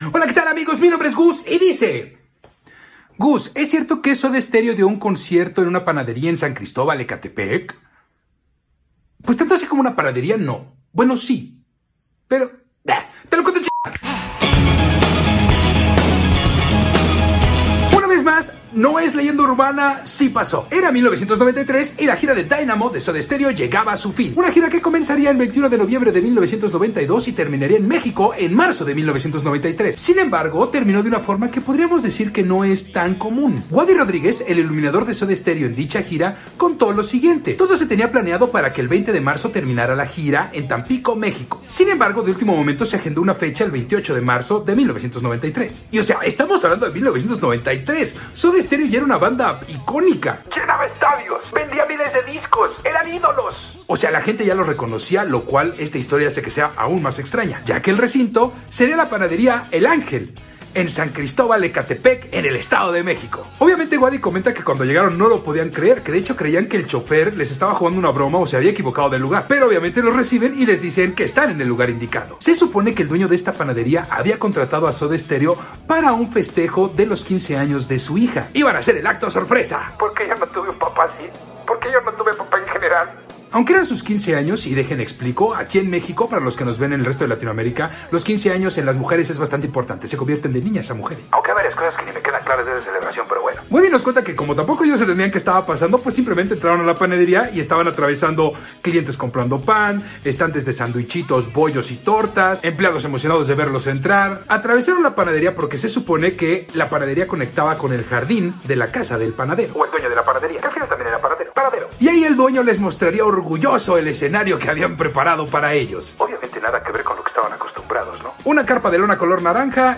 EN UNA PANADERÍA EN MÉXICO